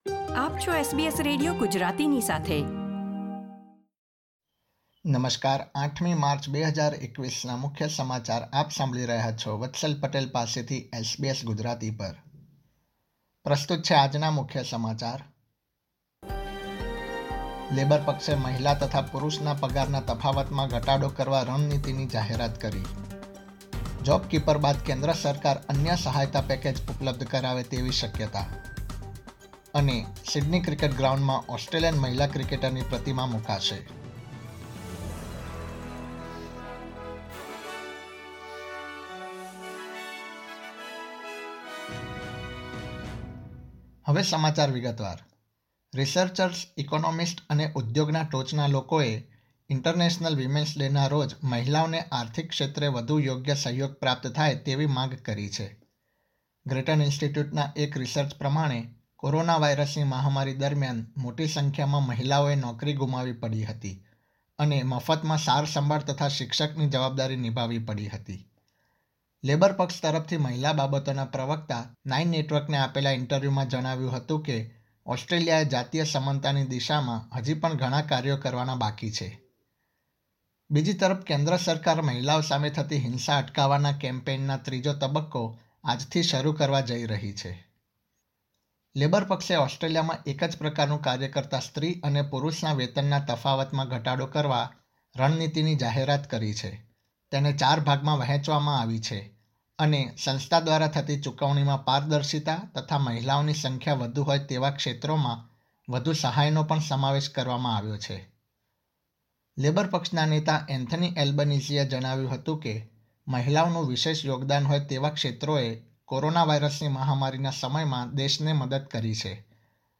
SBS Gujarati News Bulletin 8 March 2021
gujarati_0803_newsbulletin.mp3